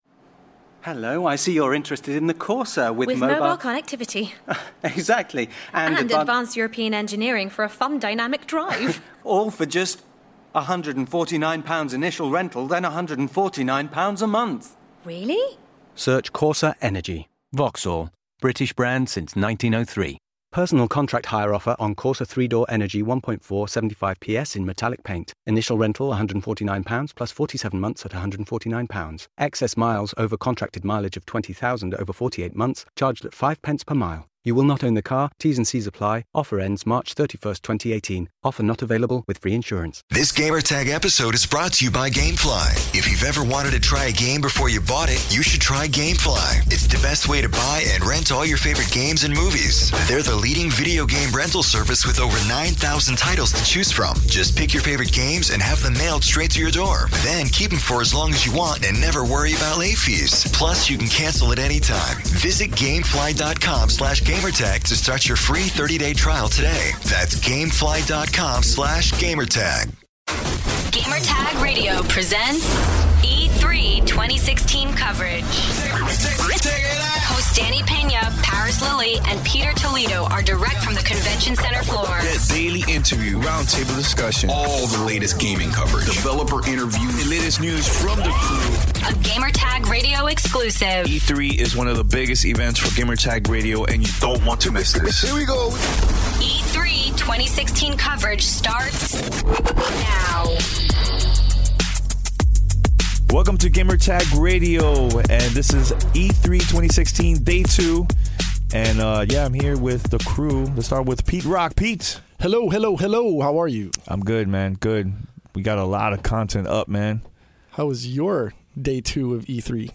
E3 2016 day 2 roundtable discussion